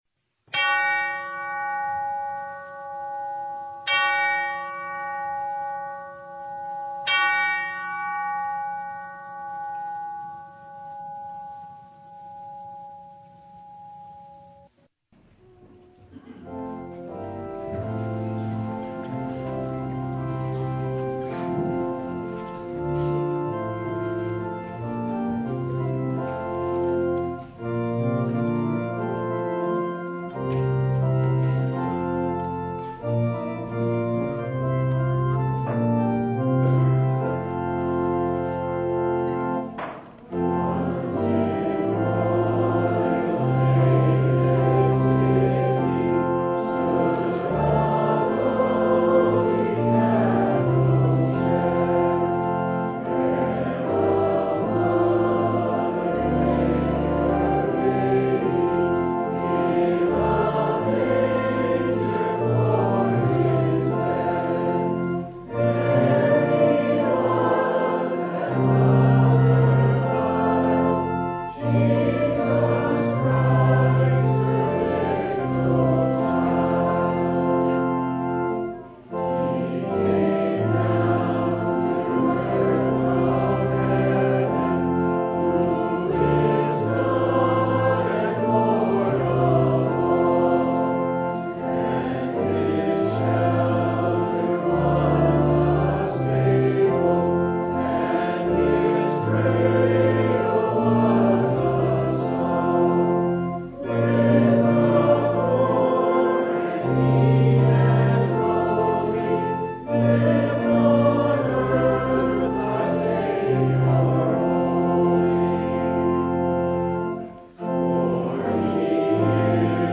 Service Type: Sunday
Download Files Bulletin Topics: Full Service « Their Death Proclaims Jesus Who’s Really Lost (Not Jesus!)